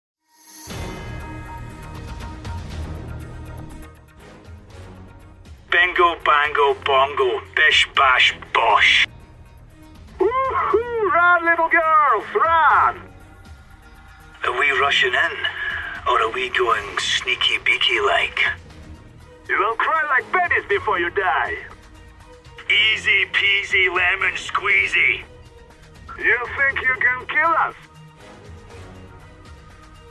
Best Agent Voice Lines in sound effects free download